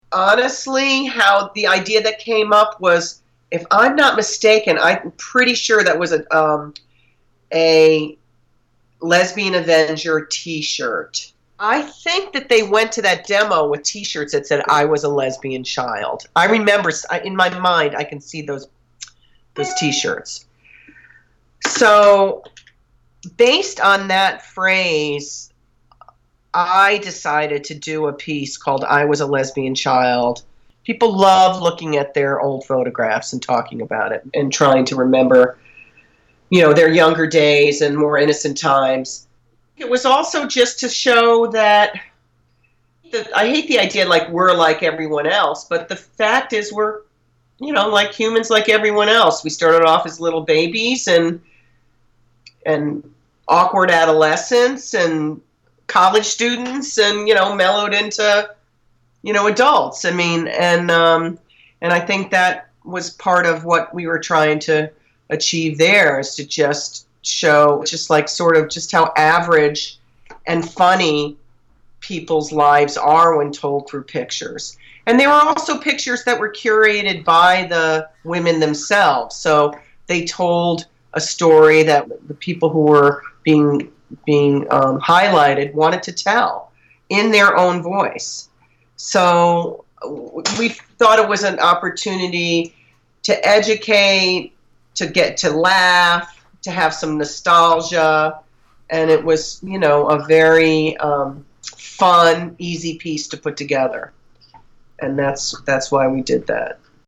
[interviewer]
[interviewee]